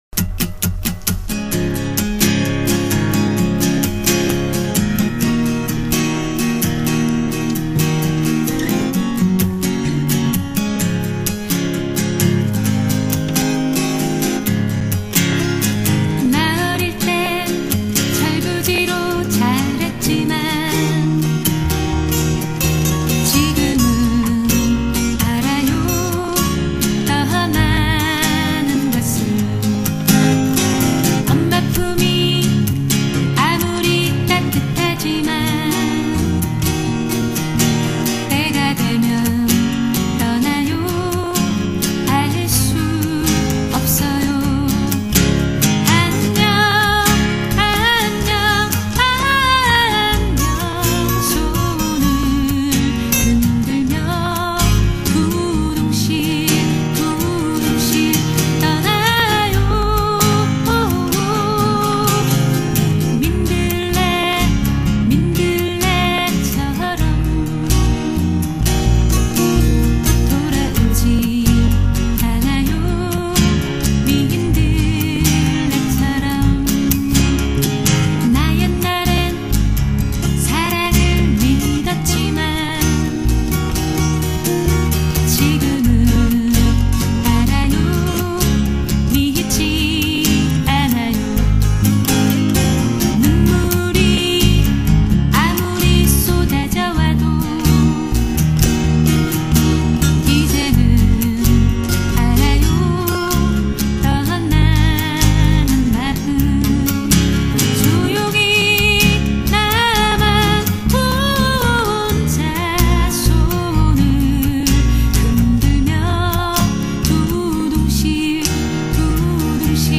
10년 가까이 언더그라운드에서 갈고닦은 여성 포크뮤지션